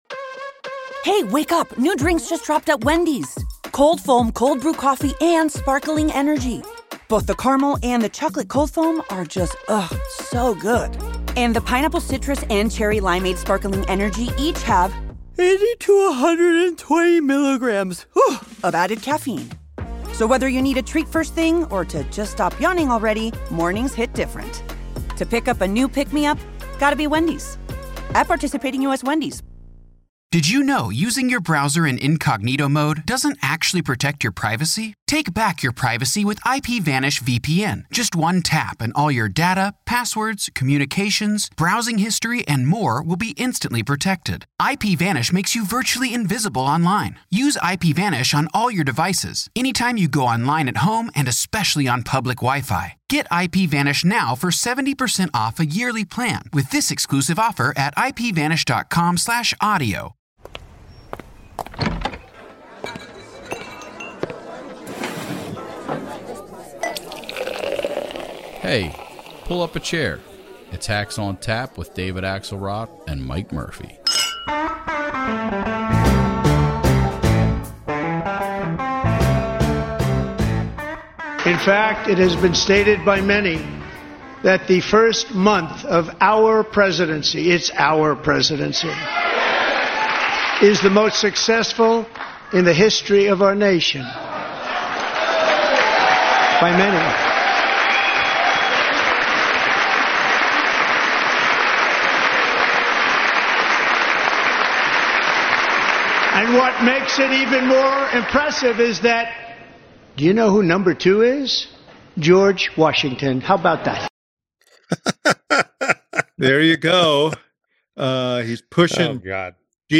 Three bleary-eyed Hacks assemble this morning to break down Trump’s speech—because, apparently, the laws of gravity and politics no longer apply. Axe, Murphy, and Heilemann dissect Democrats’ great appreciation for Musk, debate Elon’s possible motives, unravel a parliament of fluffers, and, of course, gold chainsaws.